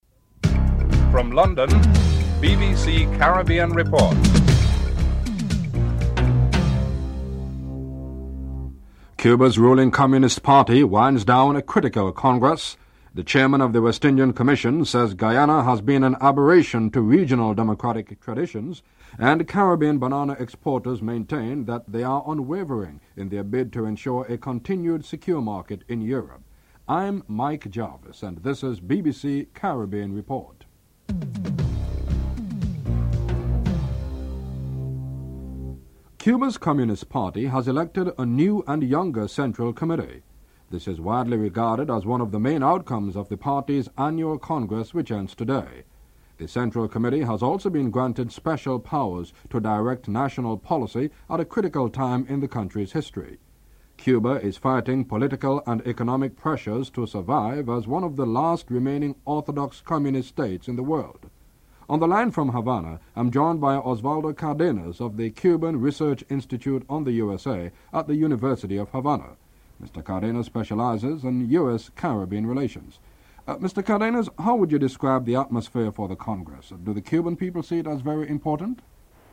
1. Headlines (00:00-00:36)